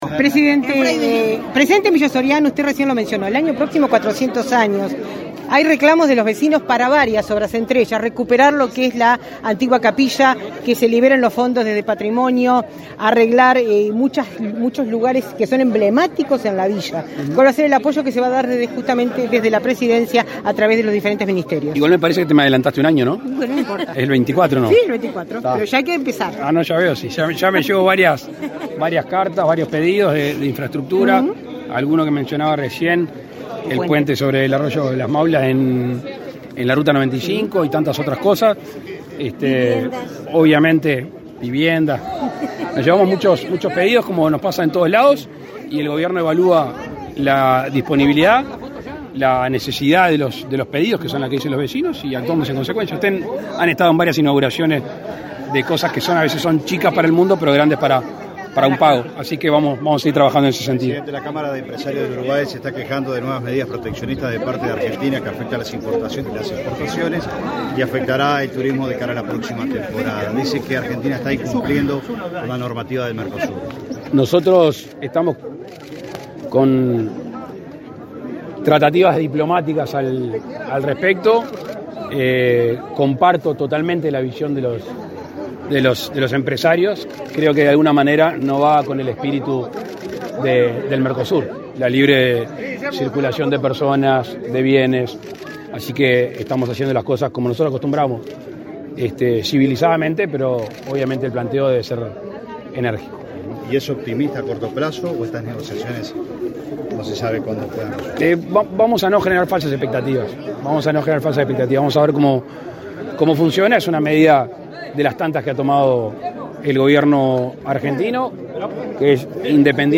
Declaraciones a la prensa del presidente de la República, Luis Lacalle Pou, en Villa Soriano